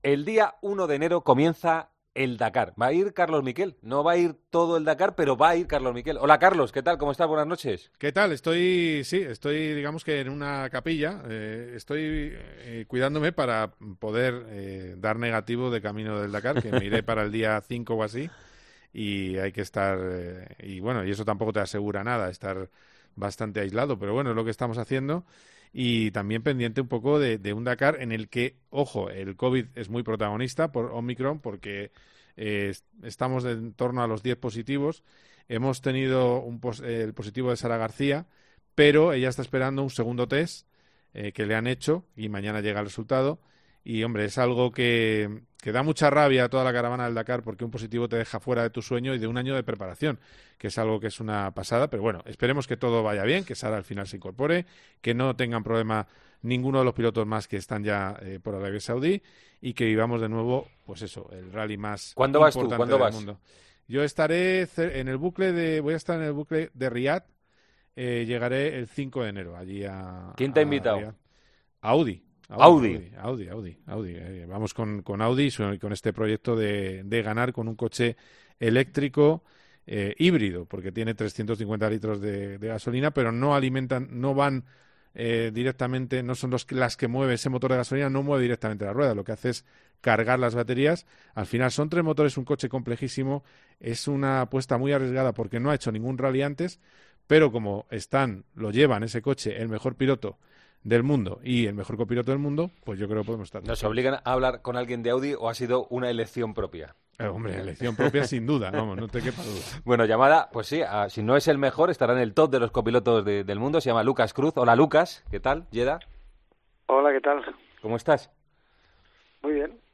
El copiloto de Carlos Sainz nos explica en El Partidazo de COPE cómo será la próxima edición del Dakar y lo que espera de ella junto al piloto madrileño.